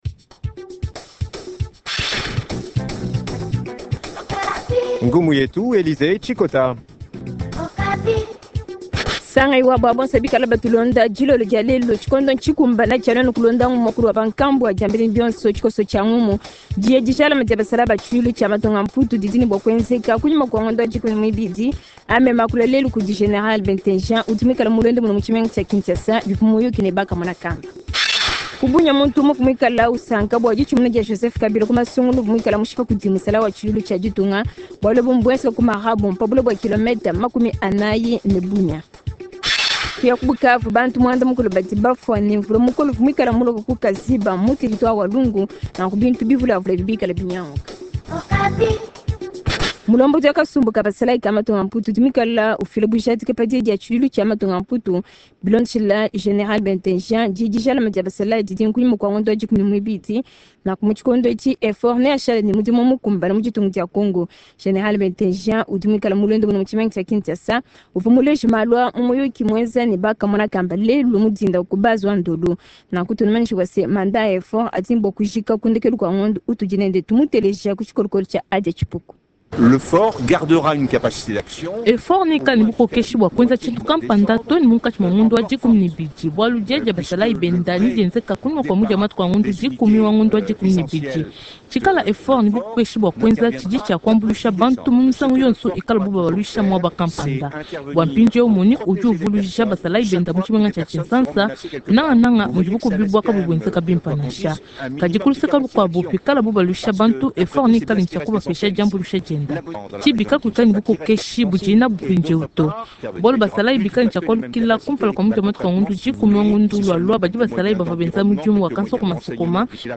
Journal Tshiluba